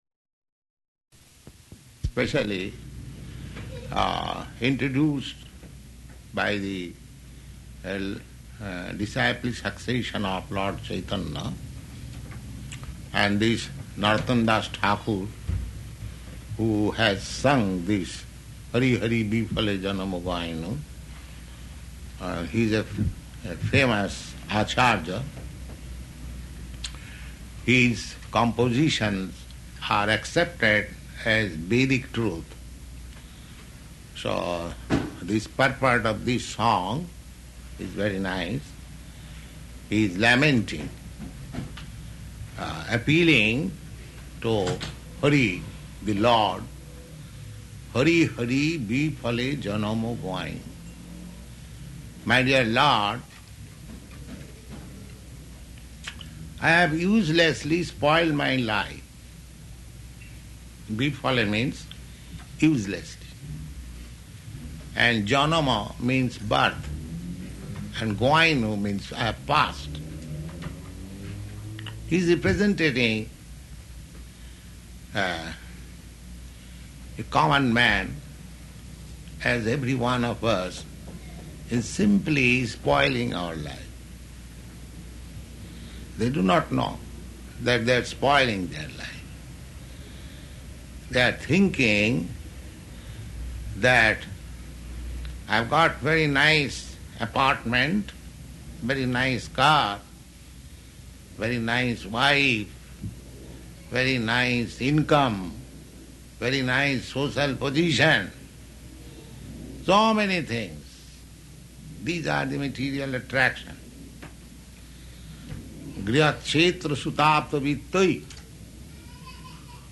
Lecture on Hari Hari Biphale
Type: Lectures and Addresses
Location: Los Angeles
[child crying] [aside:] Stop it.